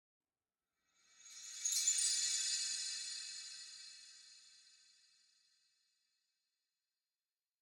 Minecraft Version Minecraft Version 1.21.5 Latest Release | Latest Snapshot 1.21.5 / assets / minecraft / sounds / ambient / nether / crimson_forest / shine1.ogg Compare With Compare With Latest Release | Latest Snapshot